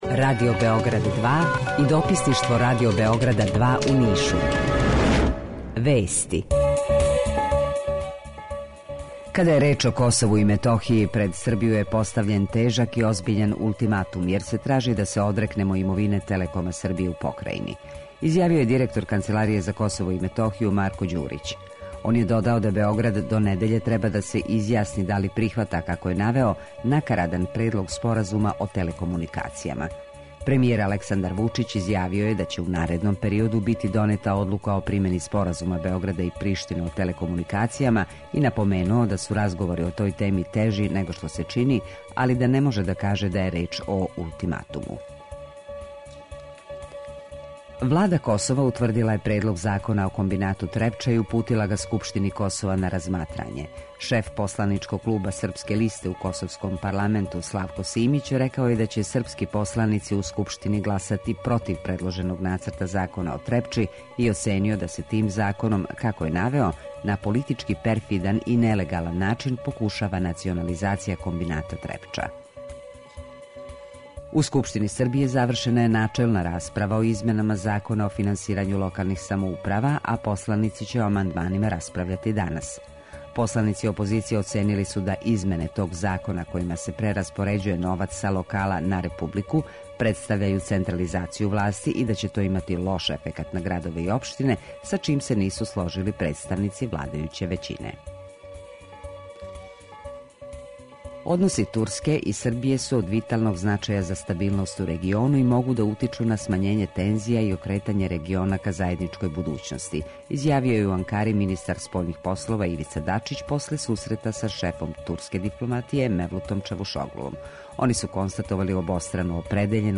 У два сата биће и добре музике, другачије у односу на остале радио-станице.